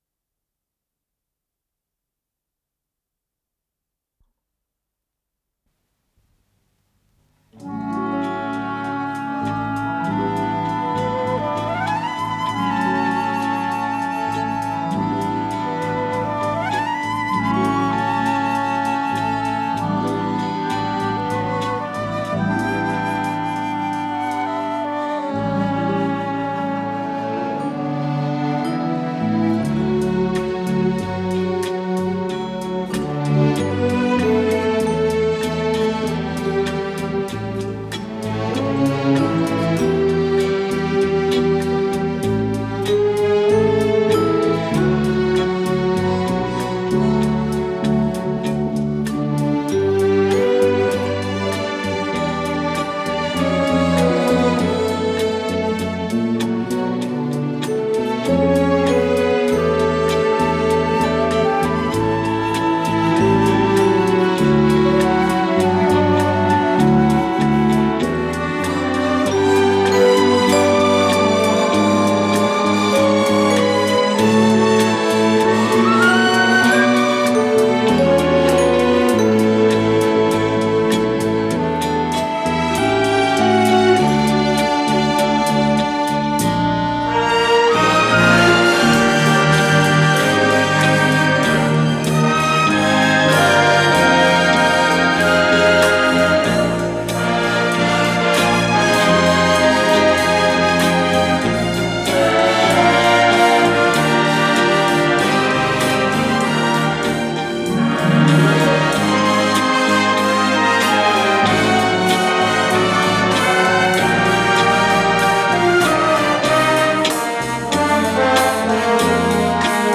ПодзаголовокДля эстрадного оркестра, ре мажор
КомпозиторыЭстонская народная
ИсполнителиЭстрадно-симфонический оркестр Всесоюзного радио и Центрального телевидения
ВариантДубль моно